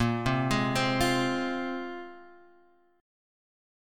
A#7sus2 Chord